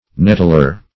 nettler - definition of nettler - synonyms, pronunciation, spelling from Free Dictionary Search Result for " nettler" : The Collaborative International Dictionary of English v.0.48: Nettler \Net"tler\, n. One who nettles.